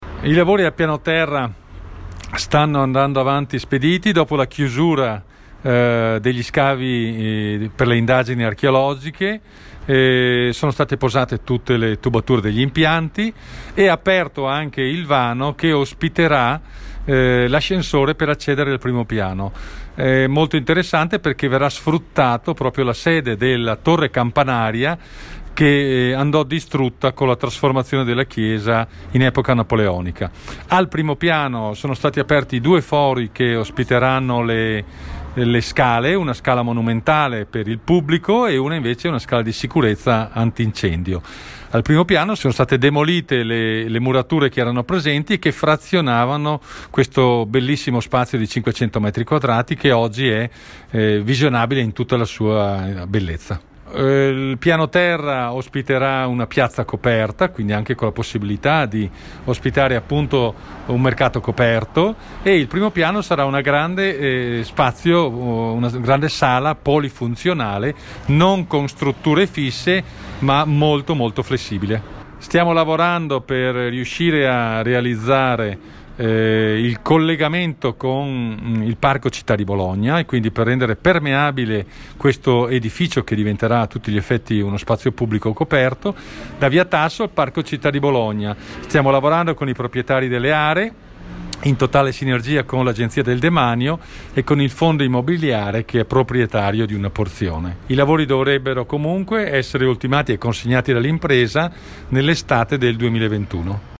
AI MICROFONI DI RADIO PIU’ L’ASSESORE FRISON